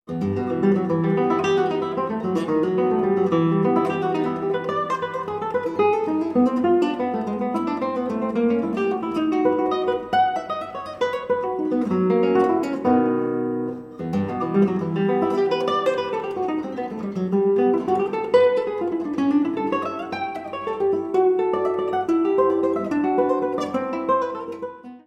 Solo Guitar and Guitar with String Orchestra